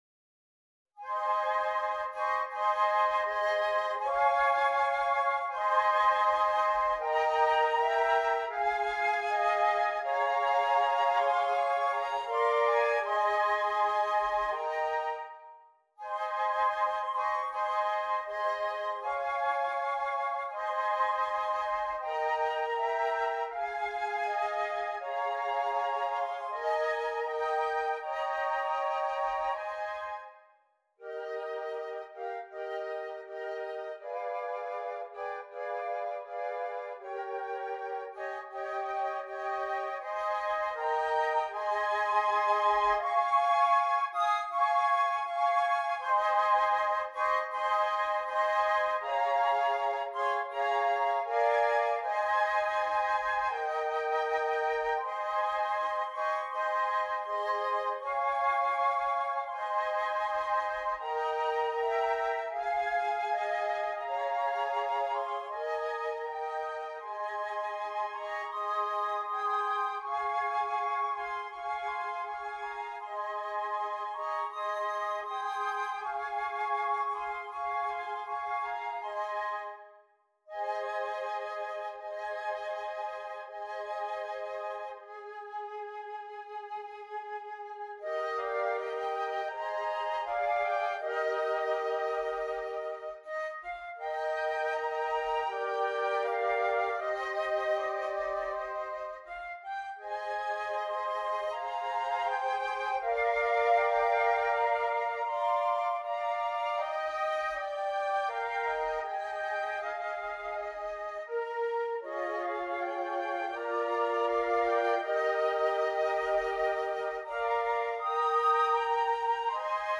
• Easy-Medium
• 4 Flutes